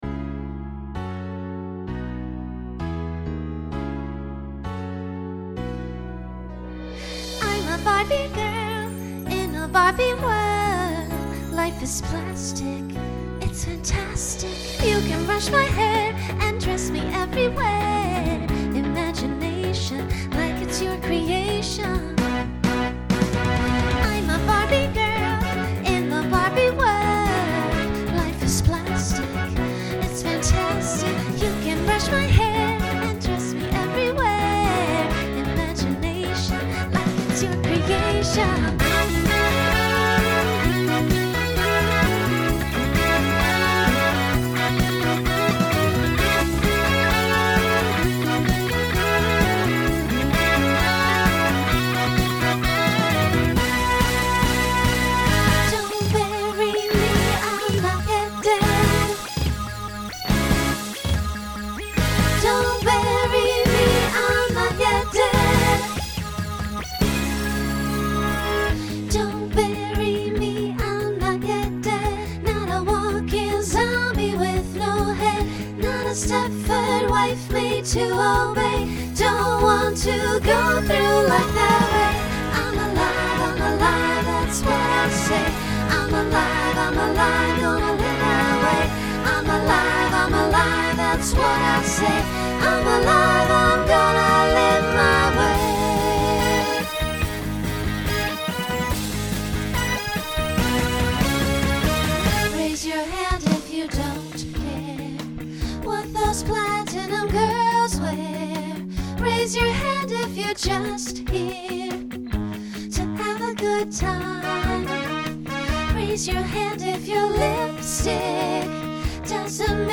Voicing SSA Instrumental combo Genre Pop/Dance , Rock